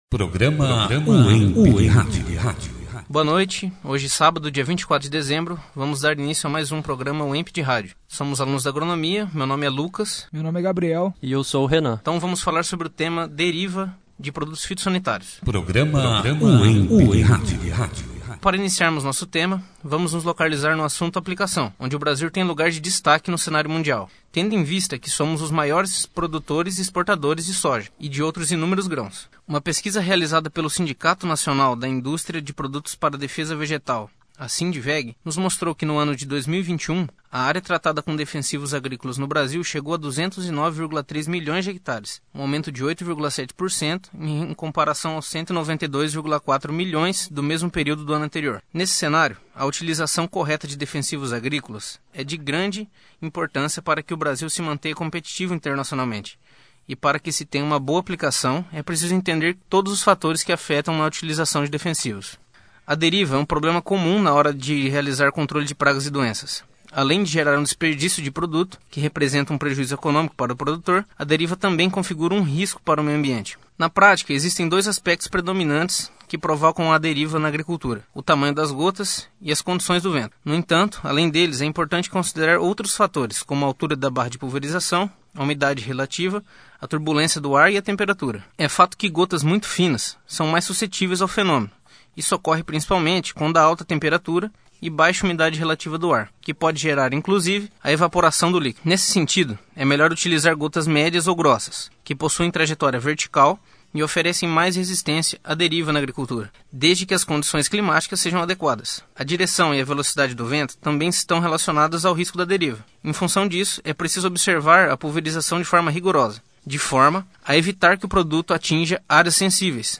Produzido e apresentado pelos alunos, Acadêmicos do 4º ano do curso de Agronomia